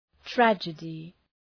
Προφορά
{‘trædʒıdı}